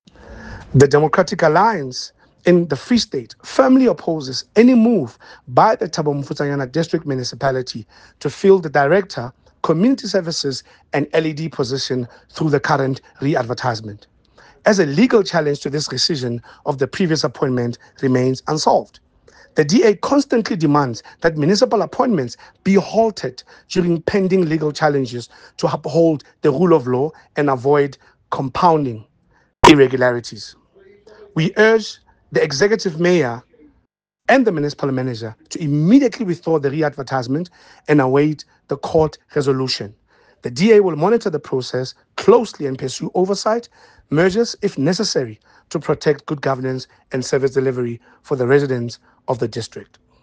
Sesotho soundbites by Cllr Eric Motloung and